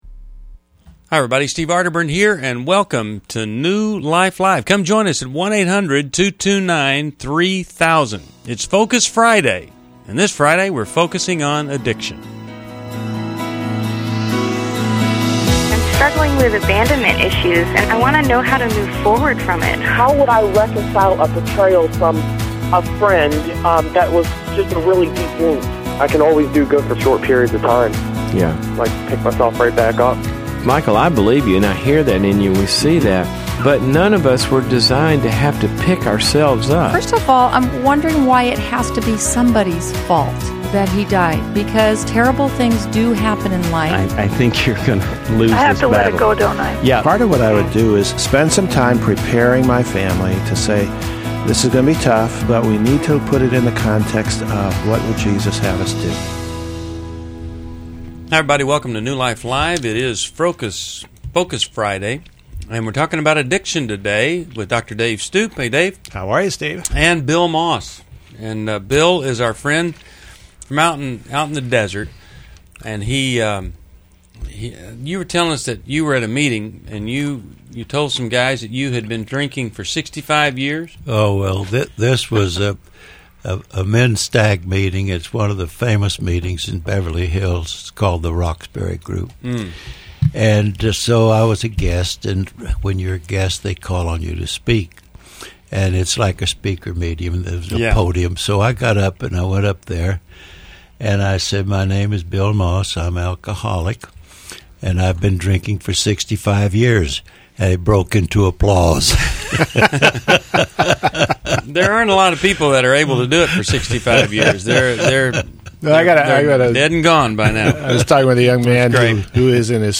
Focus Friday Caller Questions: 1.